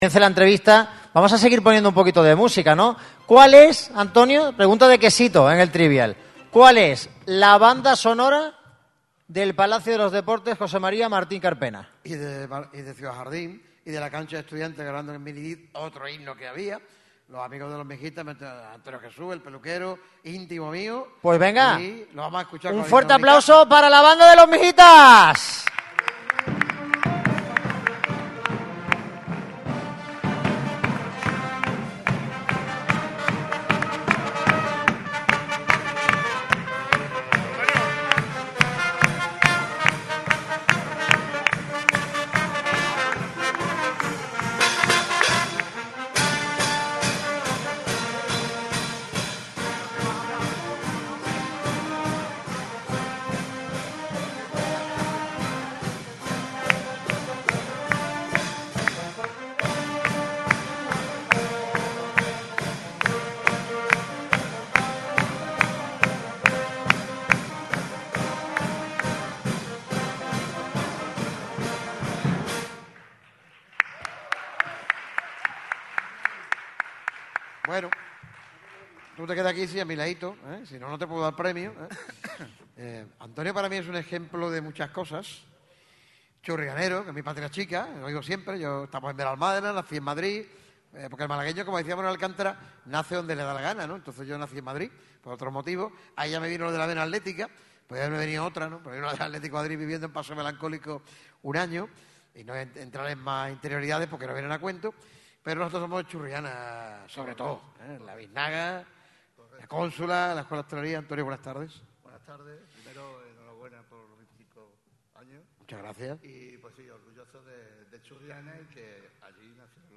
Unos nuevos premiados en la gala del 25 aniversario de Radio MARCA Málaga celebrada en Auditorio Edgar Neville de la Diputación de Málaga